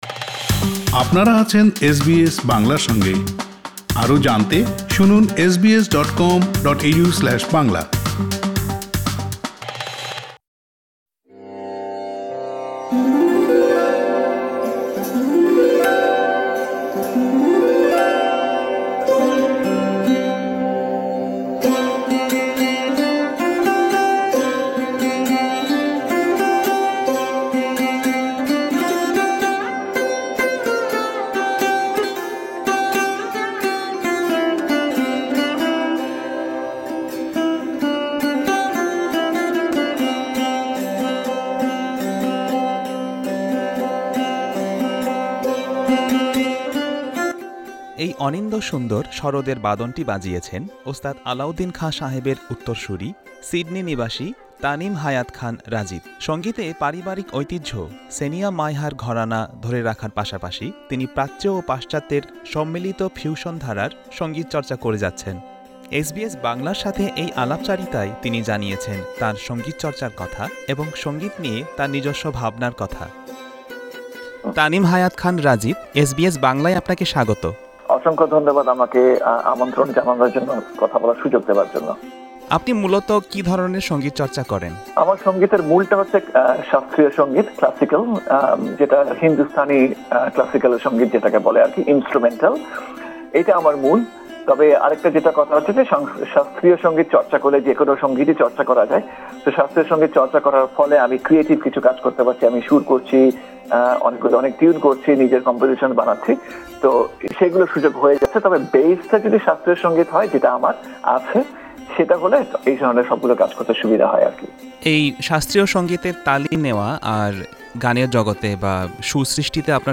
এসবিএস বাংলার সাথে এই আলাপচারিতায় তিনি জানিয়েছেন তার সঙ্গীতচর্চার কথা এবং সঙ্গীত নিয়ে তার নিজস্ব ভাবনার কথা।